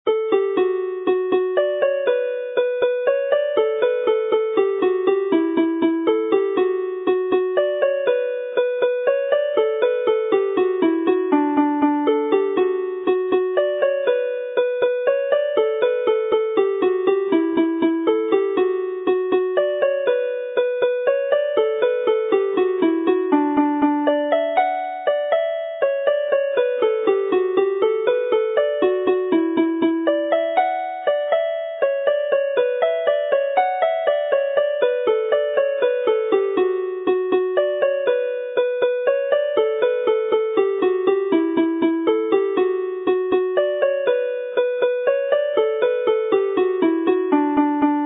plays it slowly, like a waltz.